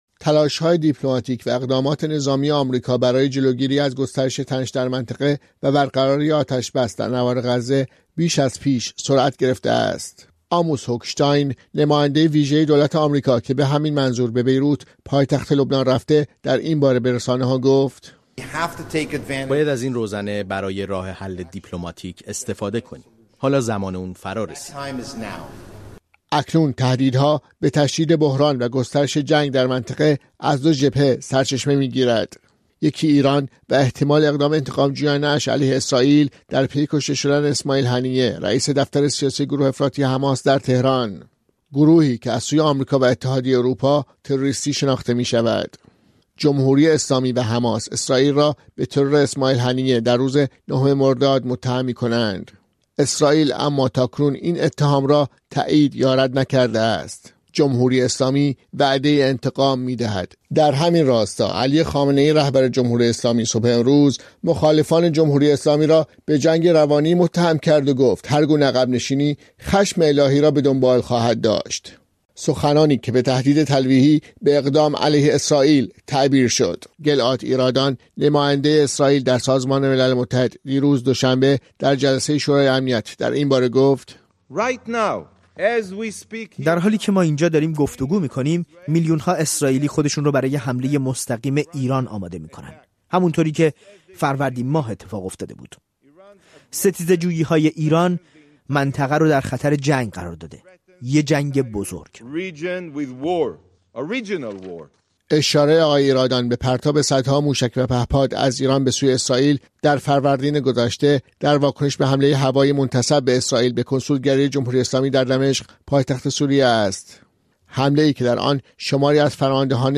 گزارش‌ رادیویی اقدام‌های آمریکا برای جلوگیری از گسترش جنگ در خاورمیانه